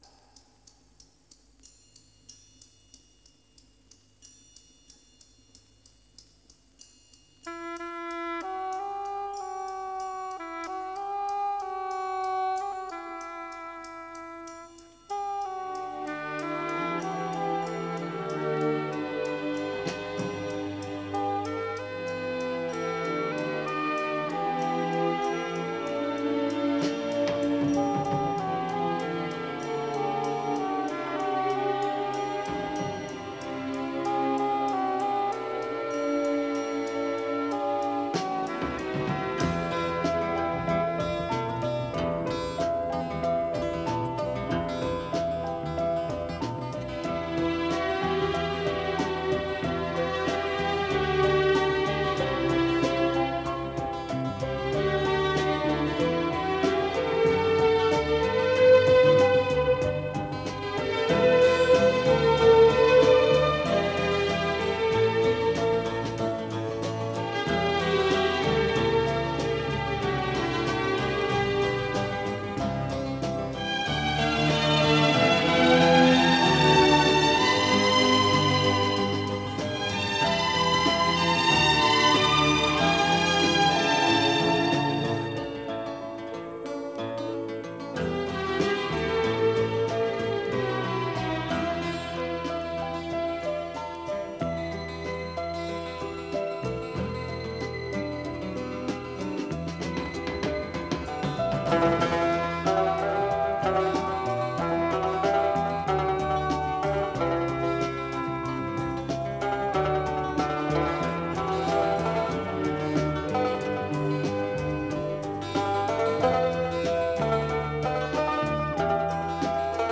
Скрипки всегда были главными «певцами» его оркестра.